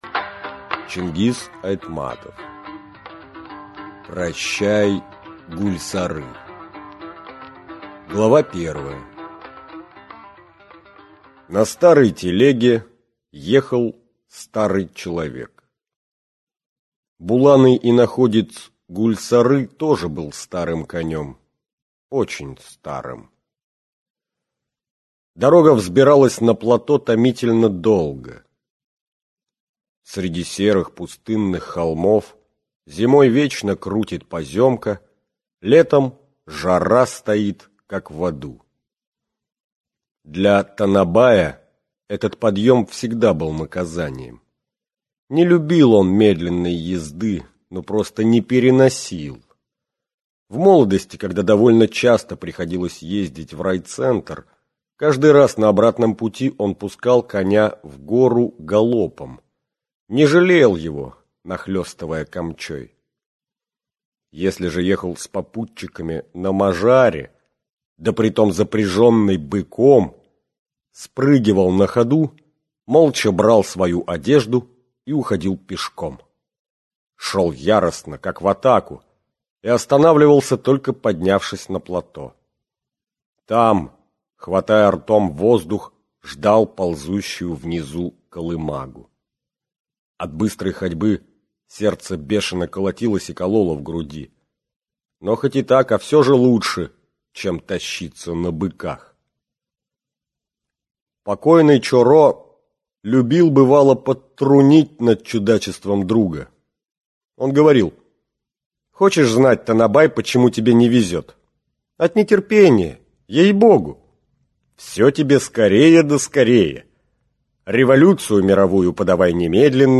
Аудиокнига Прощай, Гульсары!